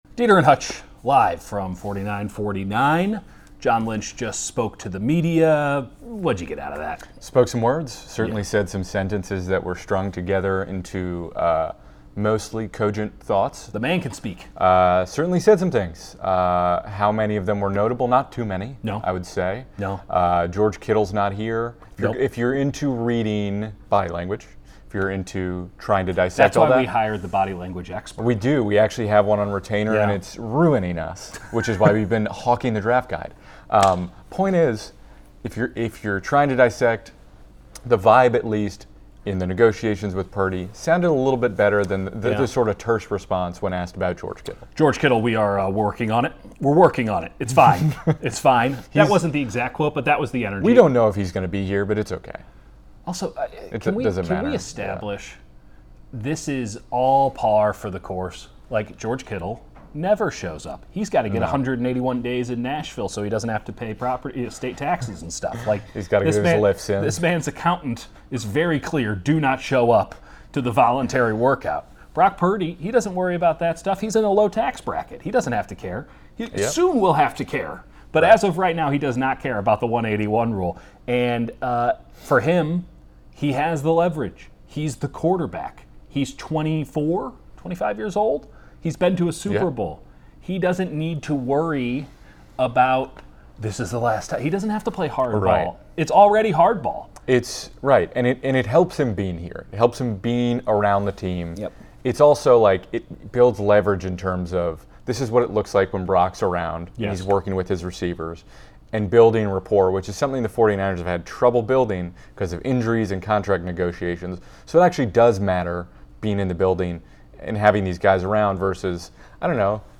record live from 49ers HQ